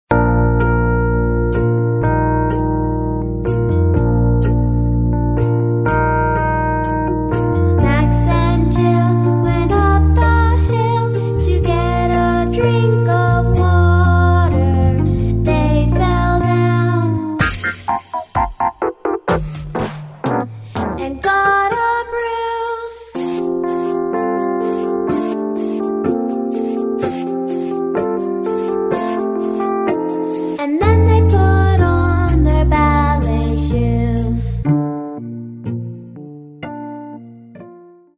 with vocal instruction